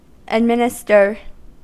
Ääntäminen
Ääntäminen US US : IPA : /ədˈmɪnɪstɚ/